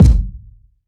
• Tight Kick Drum Single Hit G Key 554.wav
Royality free bass drum tuned to the G note. Loudest frequency: 184Hz
tight-kick-drum-single-hit-g-key-554-zkI.wav